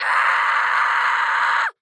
Звуки злости, ворчання
Звук юной девушки, кричащей от злости